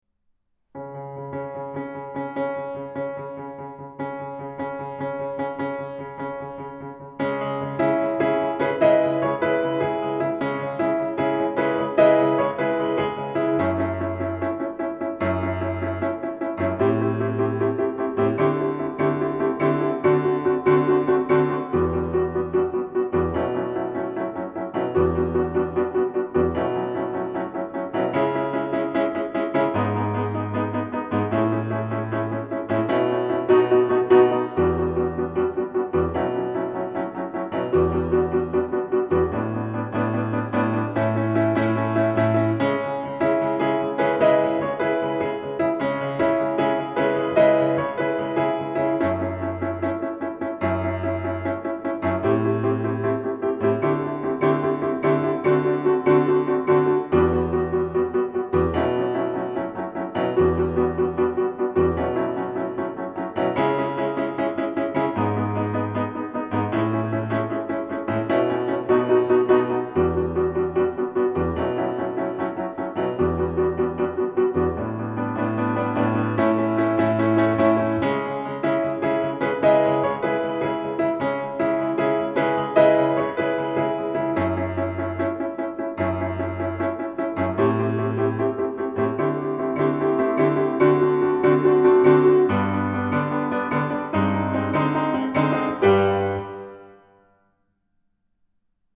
Genre :  ChansonComptine
Audio Piano seul